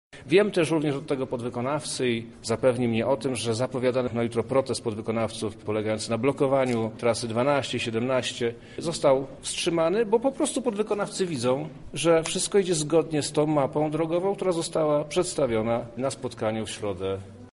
Warunkiem odwołania jutrzejszej blokady jest wpłata zaległych pieniędzy. Przemysław Czarnek rozmawiał na ten temat z jednym z podwykonawców: